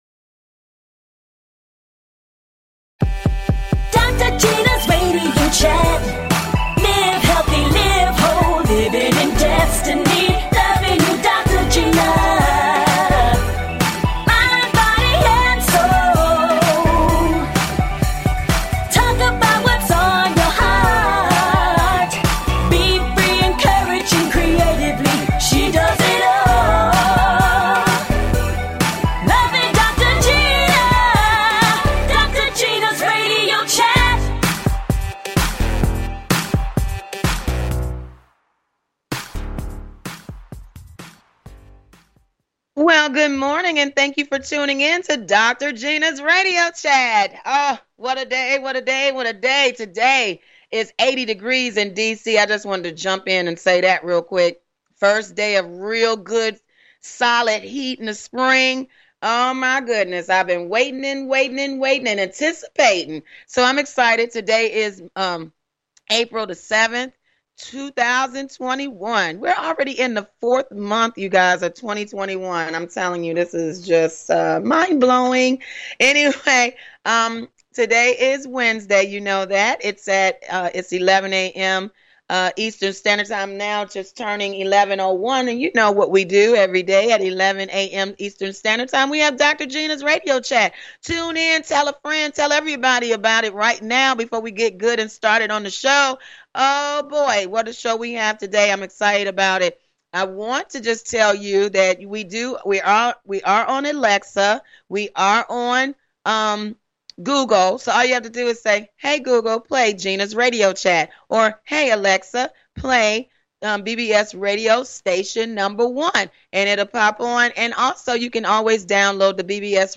A talk show of encouragement.